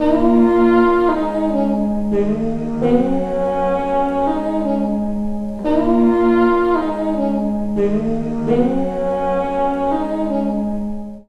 03J-SAX-.A-R.wav